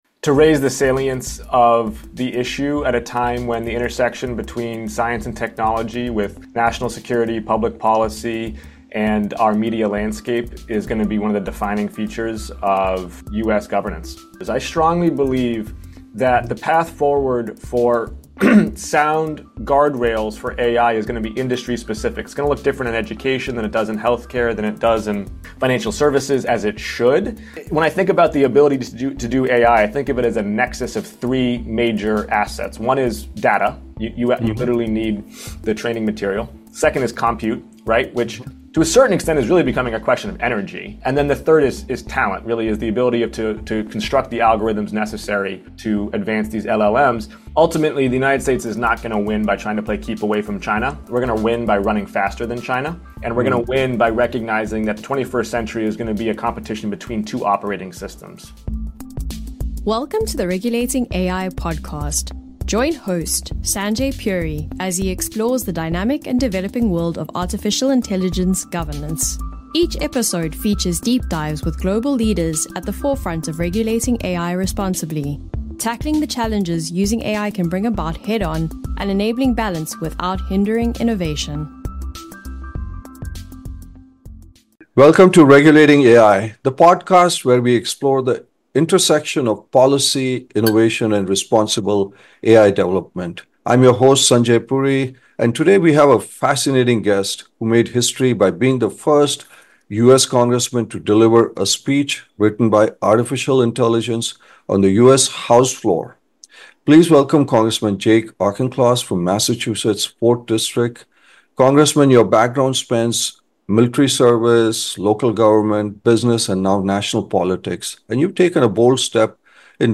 🎙In this insightful session, the panelists discuss the challenges and urgency of establishing responsible AI governance in a rapidly evolving landscape. Dive into a conversation on AI ethics, accountability, and regulation that highlights the need for thoughtful, global solutions.